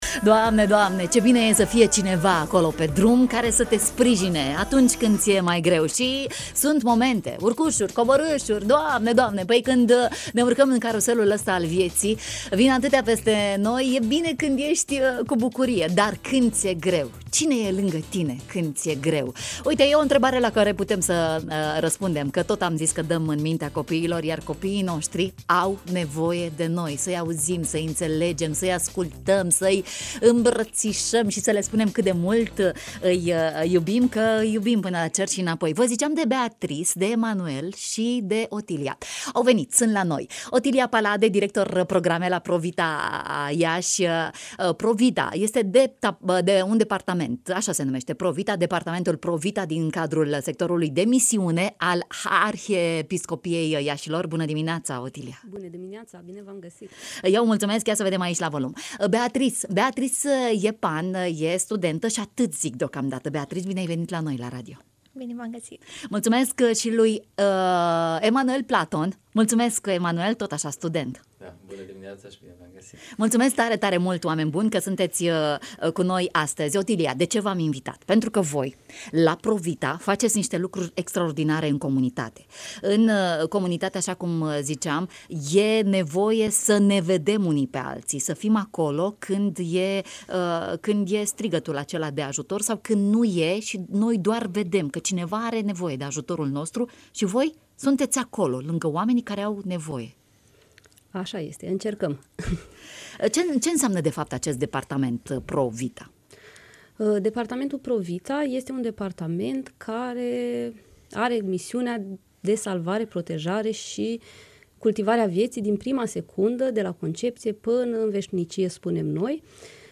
Copii buni…. în direct la matinalul de la Radio România Iași: